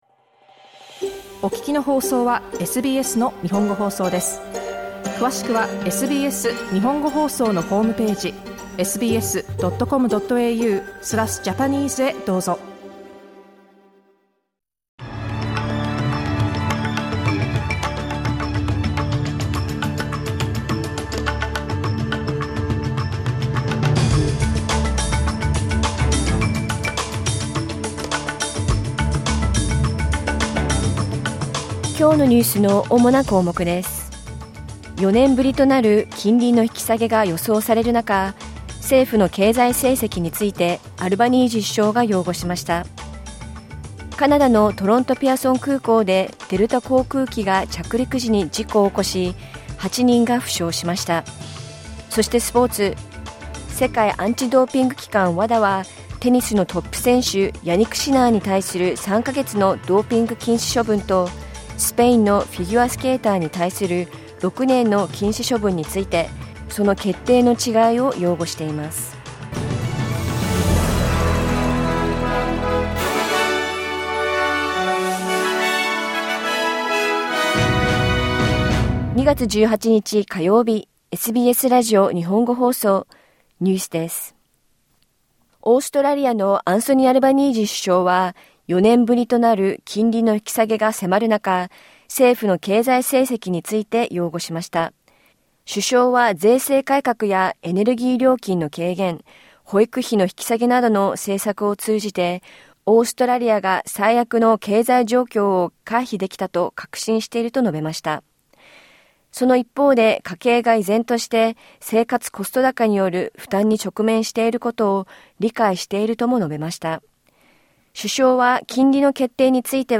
4年ぶりとなる金利の引き下げが予想されるなか、政府の経済成績について、アルバニージー首相が擁護しました。カナダのトロント・ピアソン空港で、デルタ空港機が着陸時に事故を起こし、8人が負傷しました。午後１時から放送されたラジオ番組のニュース部分をお届けします。